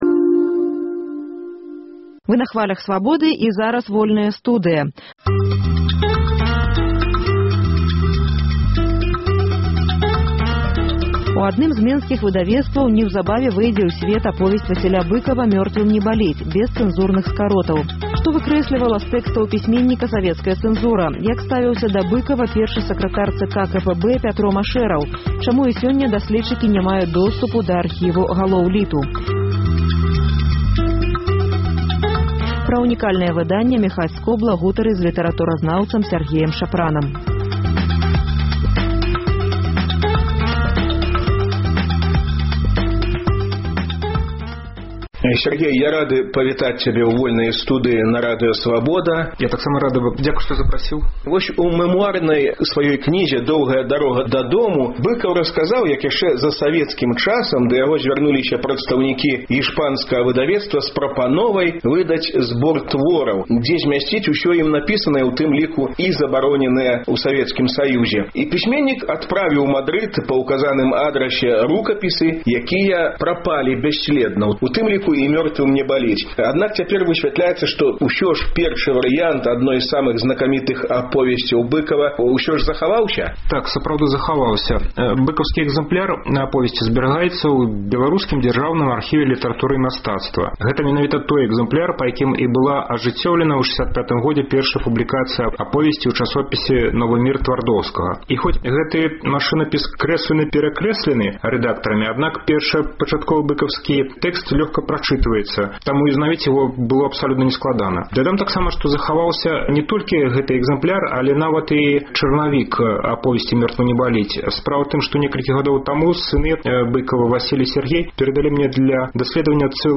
гутарыць зь літаратуразнаўцам